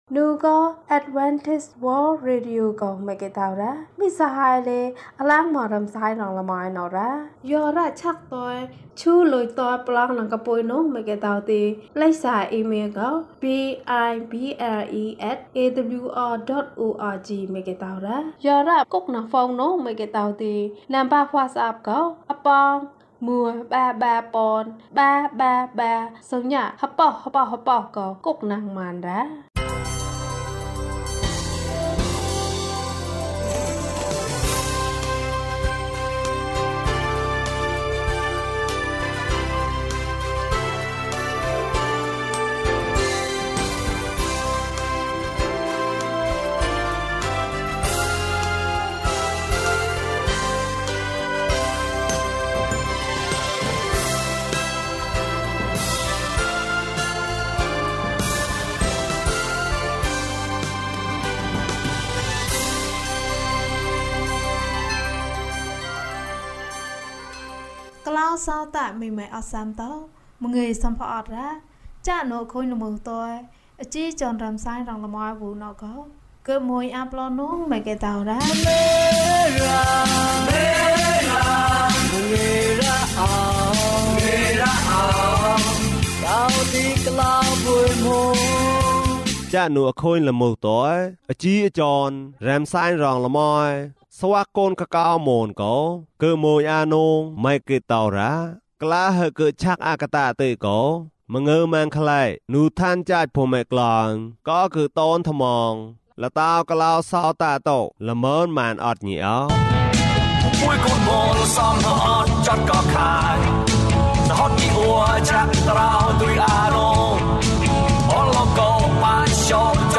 သင့်အသက်တာတွင် ဘုရားသခင်ကို ကိုးစားပါ။၀၁ ကျန်းမာခြင်းအကြောင်းအရာ။ ဓမ္မသီချင်း။ တရားဒေသနာ။